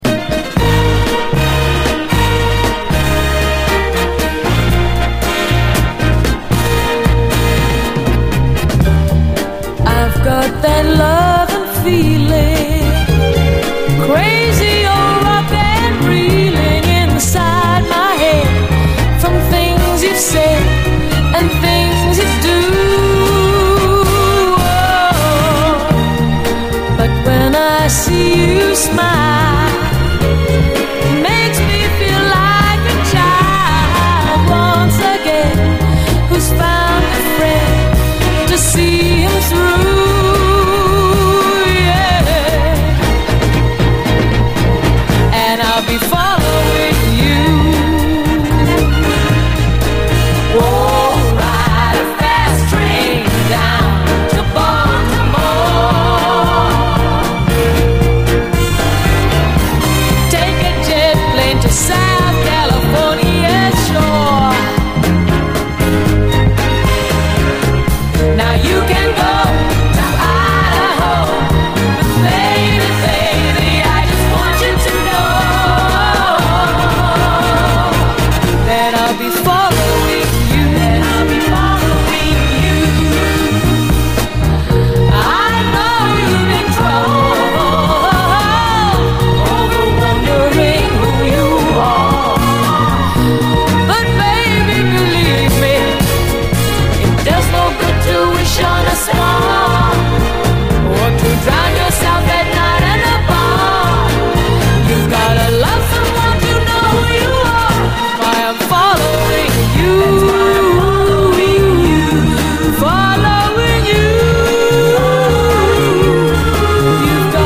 SOUL, 70's～ SOUL
ポジティヴィティーが降り注ぐ、ビューティフル・ソウル！